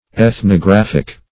ethnographic - definition of ethnographic - synonyms, pronunciation, spelling from Free Dictionary
Ethnographic \Eth`no*graph"ic\, Ethnographical